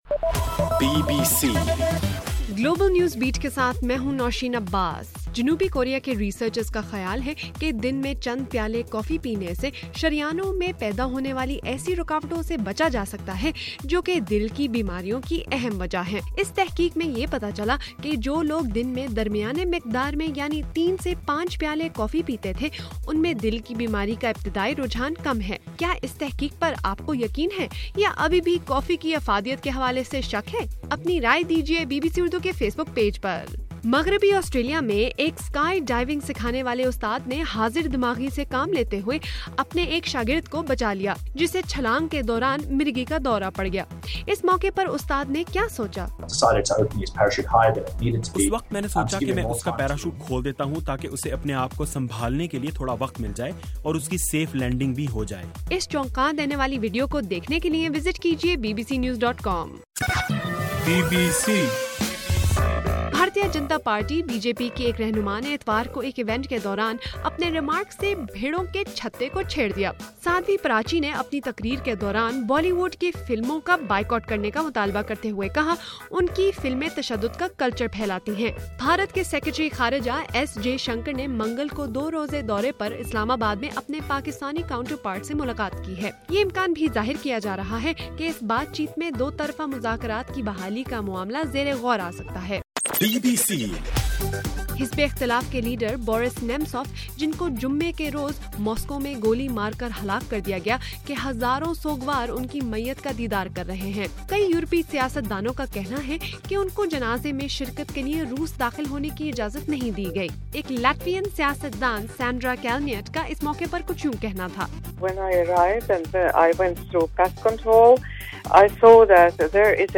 مارچ 3: رات 8 بجے کا گلوبل نیوز بیٹ بُلیٹن